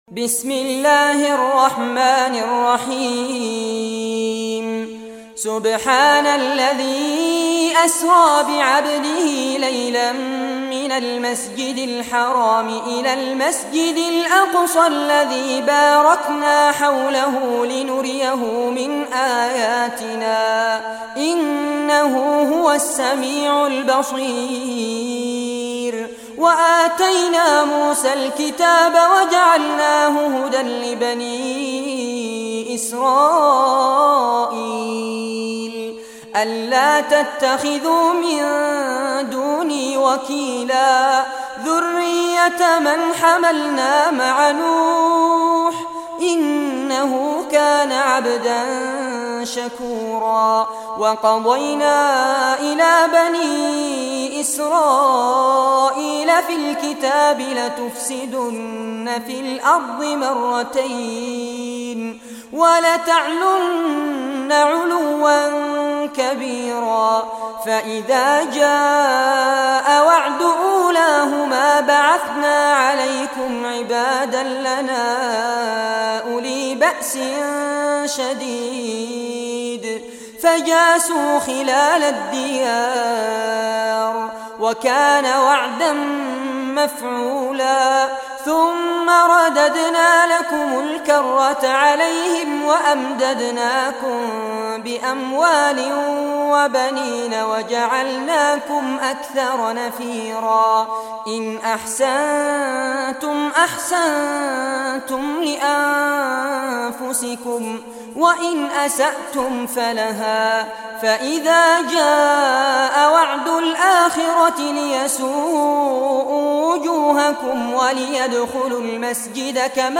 Surah Al-Isra Recitation by Sheikh Fares Abbad
Surah Al-Isra, listen or play online mp3 tilawat / recitation in Arabic in the beautiful voice of Sheikh Fares Abbad.